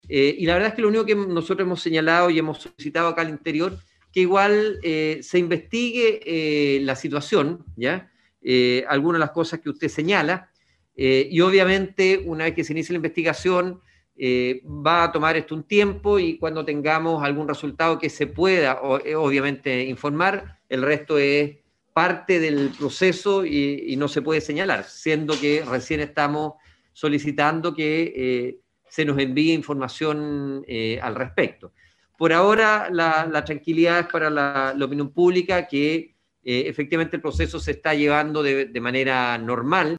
A su vez, el seremi de Salud Alejandro Caroca indicó que la situación está en investigación.